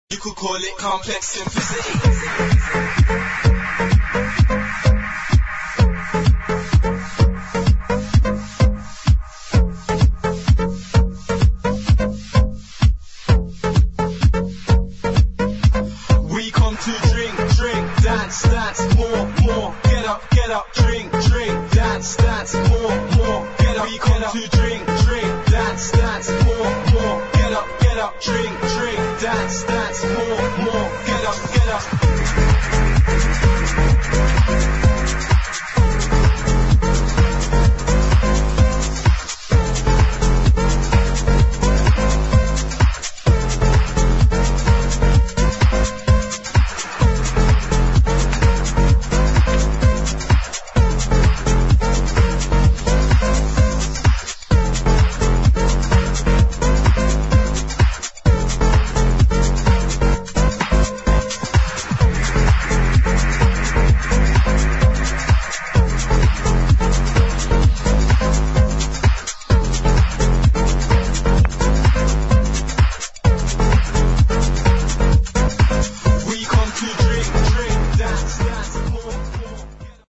[ HOUSE | ELECTRO ]